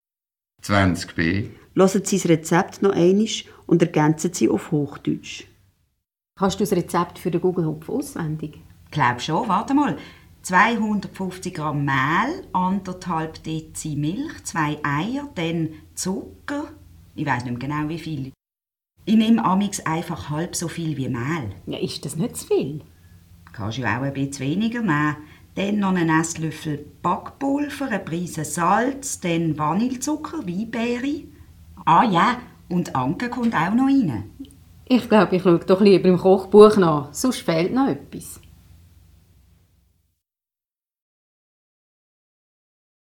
Versteh-Lektion für den Küchendienst